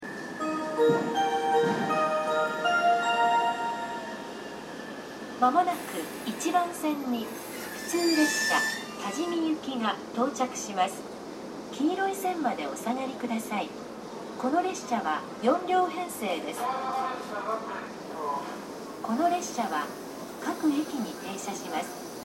この駅では接近放送が設置されています。スピーカーは各ホーム２〜３か所設置されています。
１番線CF：中央線
接近放送普通　多治見行き接近放送です。